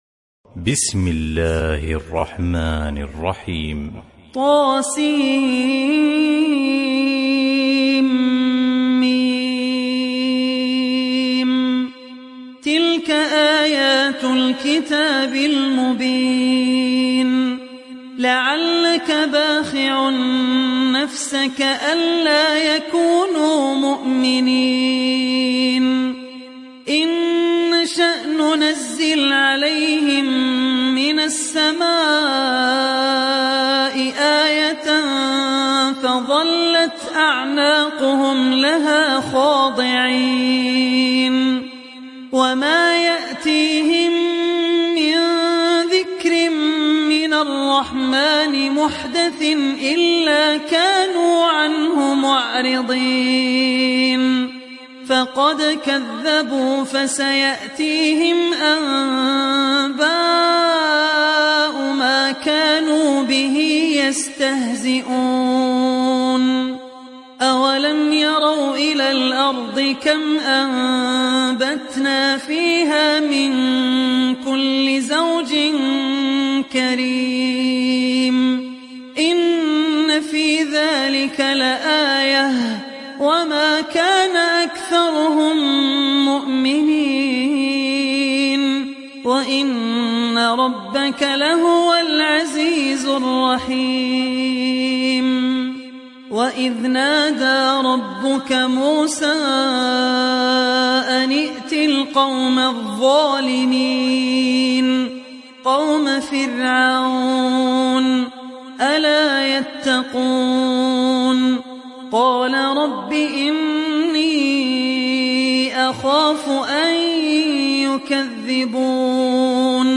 Surat Ash Shuara Download mp3 Abdul Rahman Al Ossi Riwayat Hafs dari Asim, Download Quran dan mendengarkan mp3 tautan langsung penuh